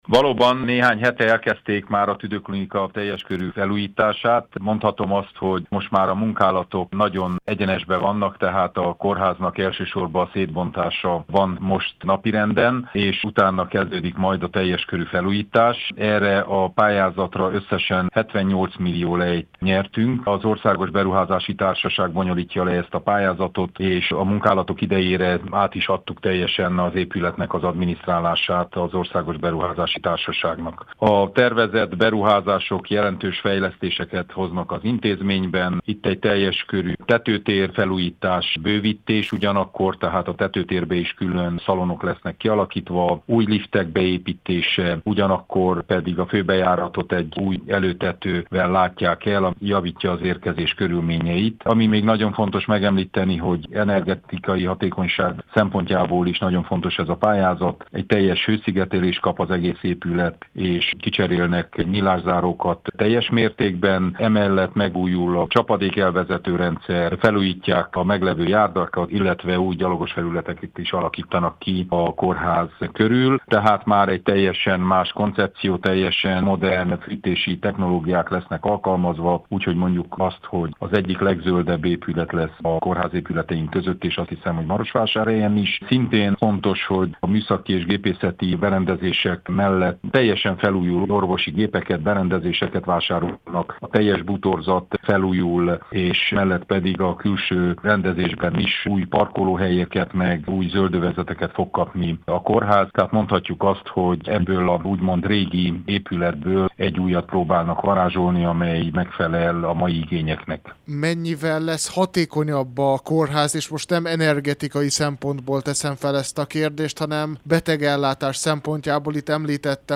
Emellett természetesen az orvosi berendezések cseréje is megtörténik új eszközökre, emelte ki Péter Ferenc, Maros megye Tanácsának elnöke, aki külön nyomatékosította, hogy két év múlva egy teljesen modern tüdőklinikája lesz a megyének.